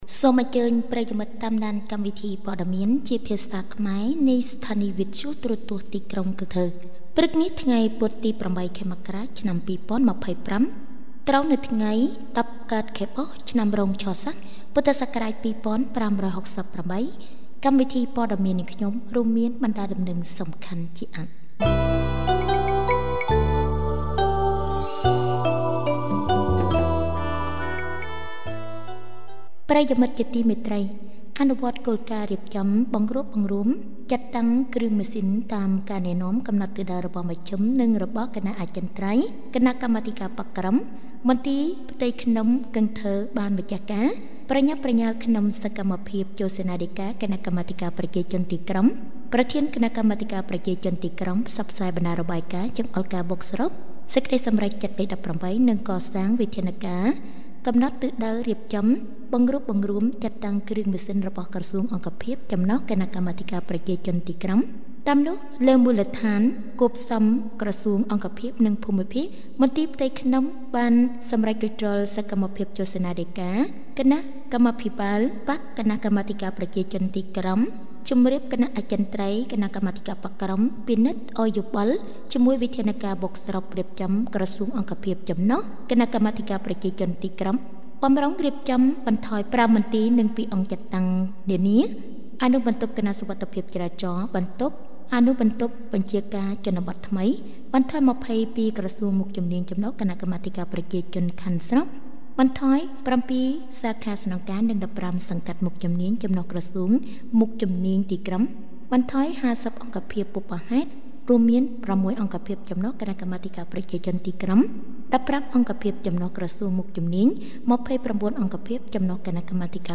Bản tin tiếng Khmer sáng 8/1/2025